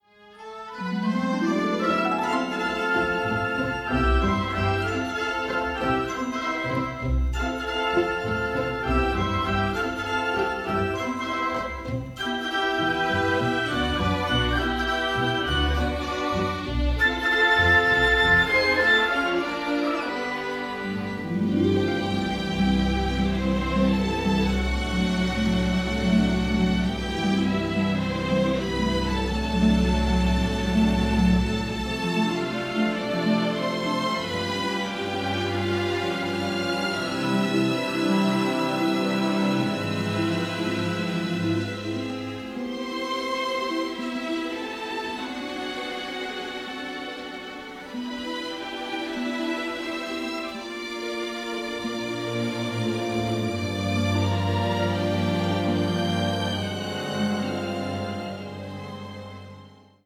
Genre Film score
Key C lydian Tempo /Style Moderate Time signature 4/4